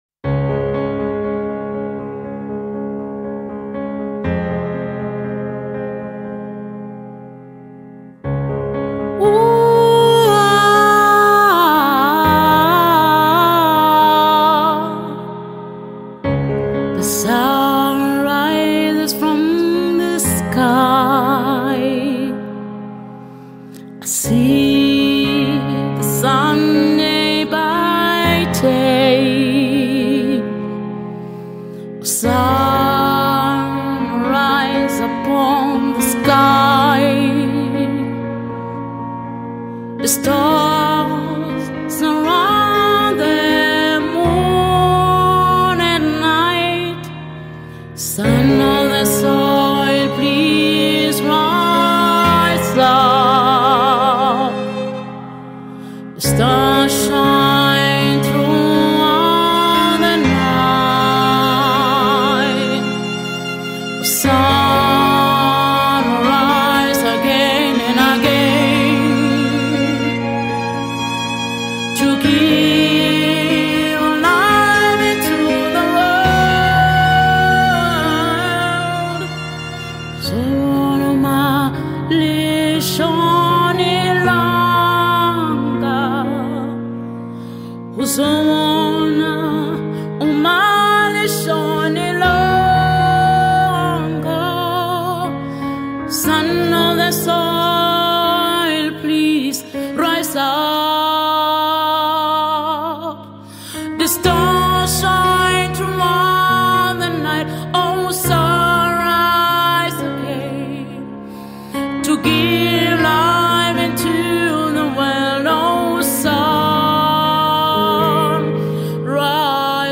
Chanteuse
- Contralto